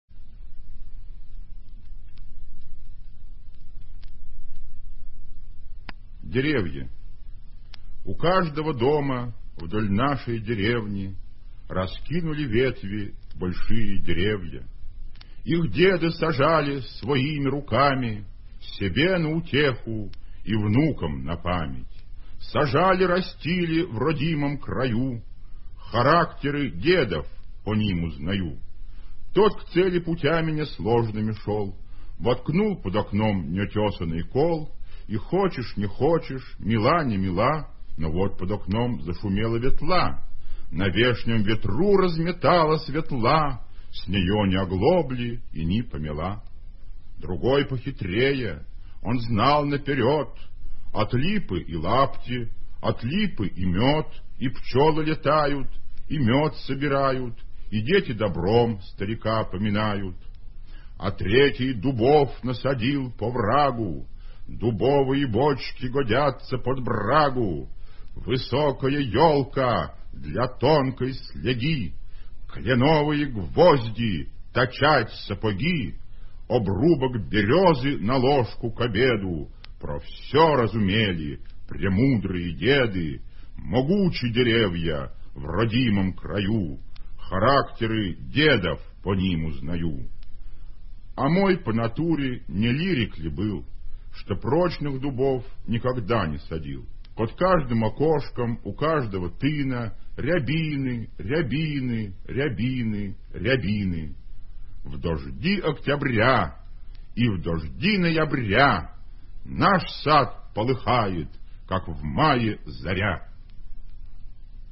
vladimir-solouhin-derevya-chitaet-avtor